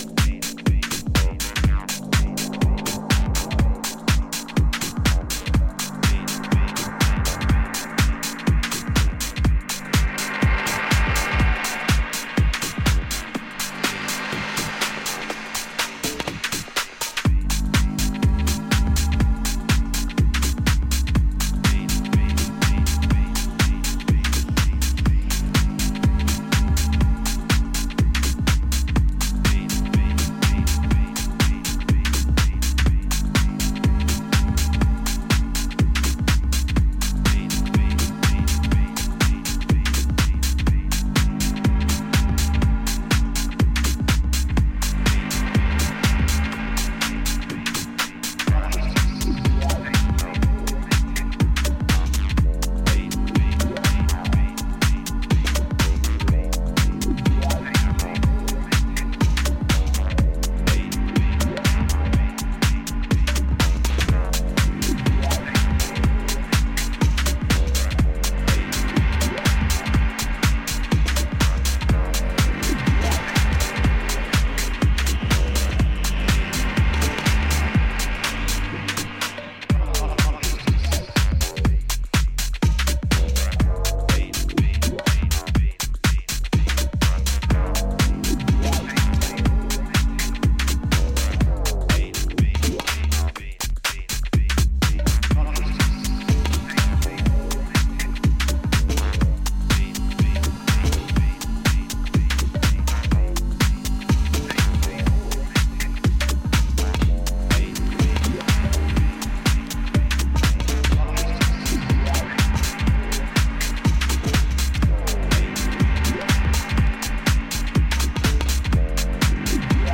120台の落ち着いたテンポで展開するソリッドなグルーヴはダンサーの気分を高揚させるフロアムードの下地作りにバッチリ。